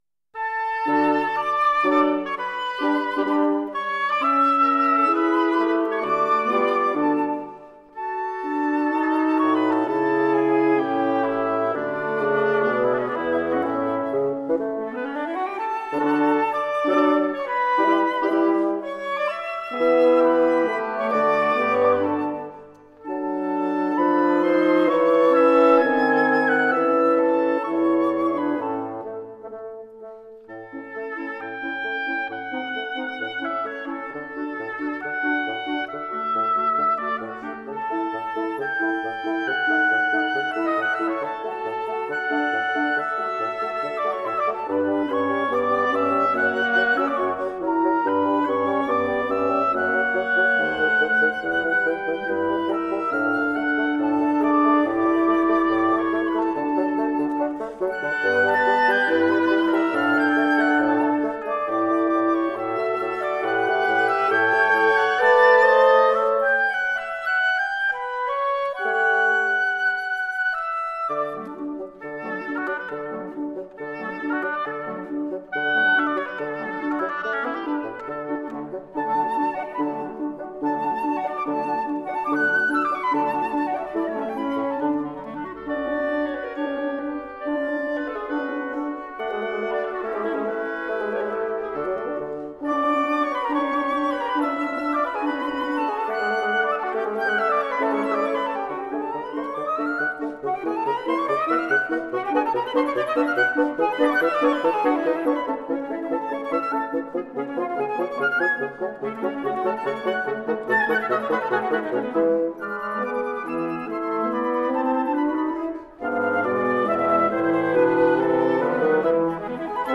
Soundbite 1st Movt
For Flute, Oboe, Clarinet, Horn, and Bassoon